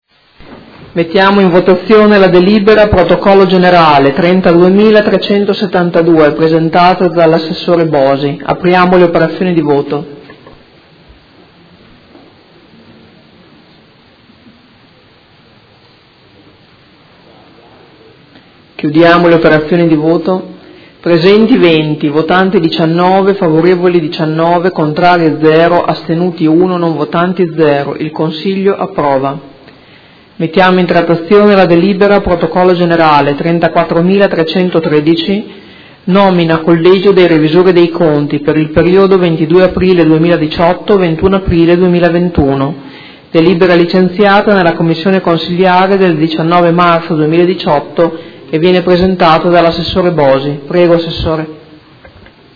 Presidentessa